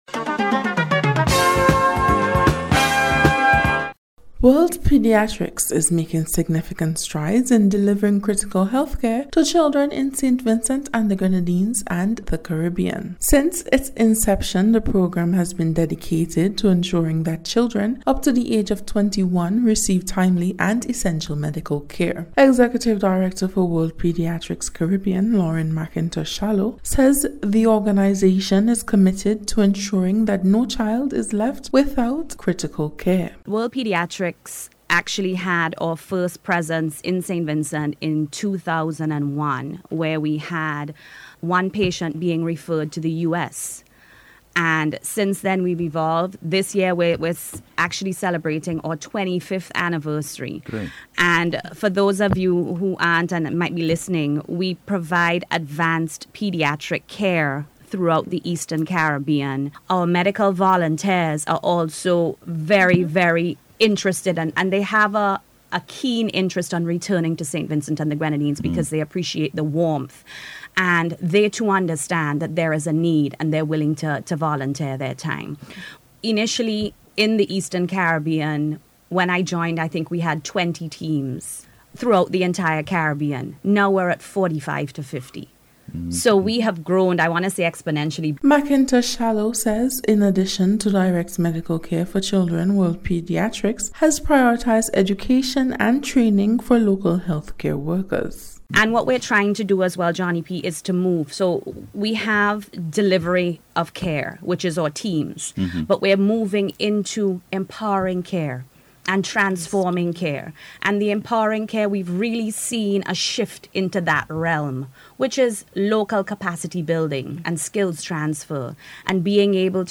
WORLD-PEDIATRICS-REPORT.mp3